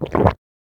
gulpliquid.ogg